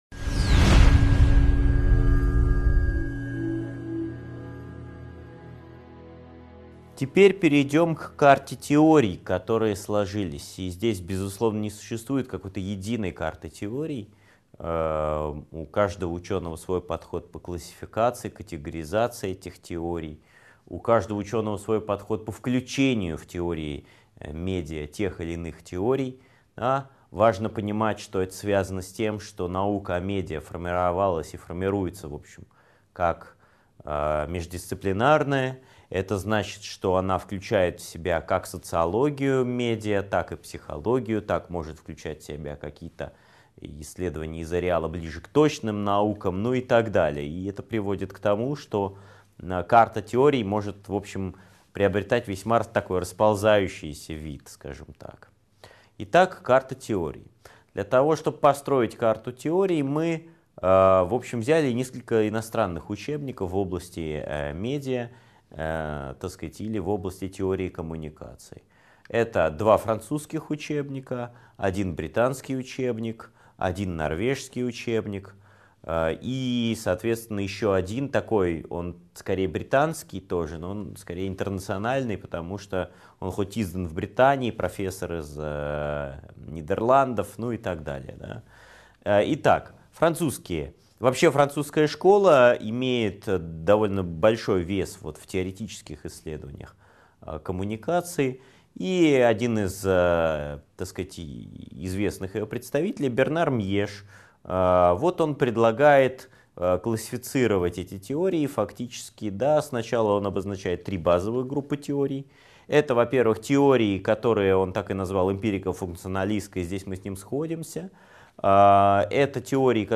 Аудиокнига 5.2 Карта теорий медиа | Библиотека аудиокниг